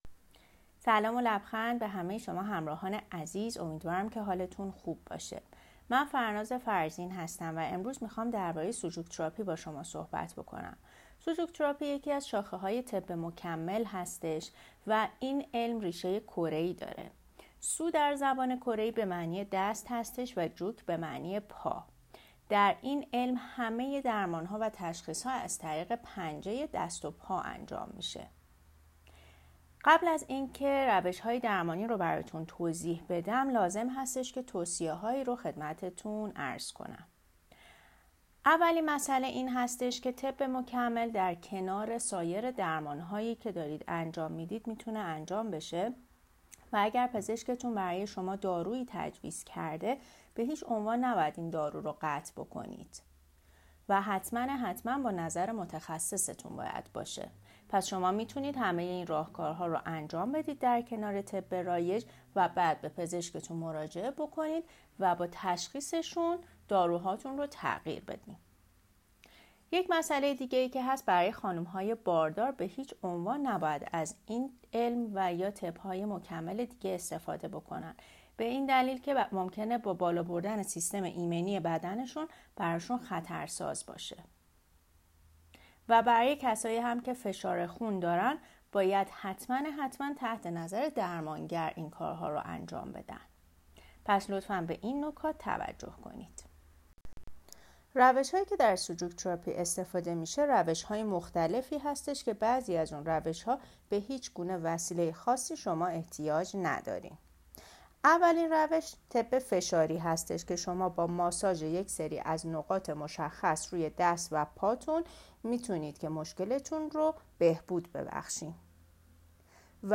مدرس